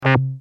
Free MP3 vintage Korg PS3100 loops & sound effects 8